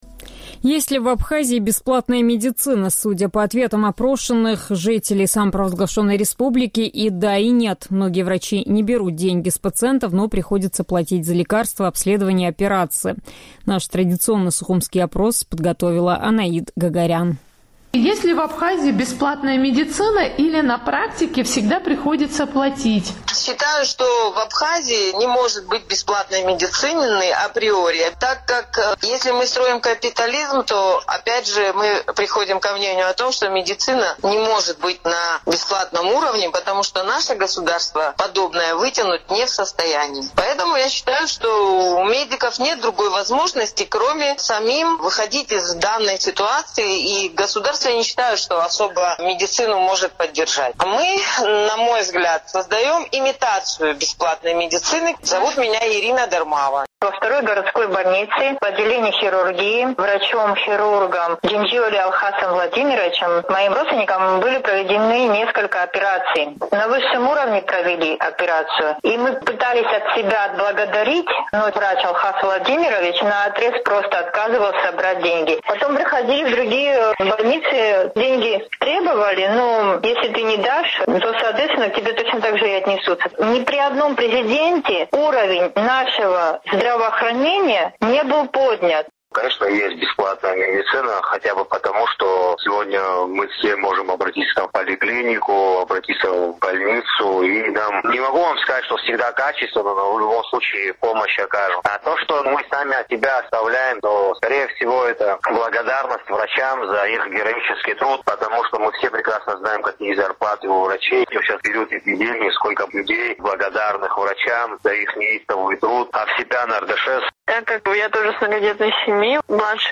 Есть ли в Абхазии бесплатная медицина? Судя по ответам опрошенных «Эхом Кавказа» местных жителей, и да, и нет: многие врачи не берут деньги с пациентов, но приходится платить за лекарства, обследования, операции и т.д.